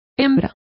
Complete with pronunciation of the translation of female.